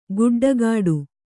♪ guḍḍagāḍu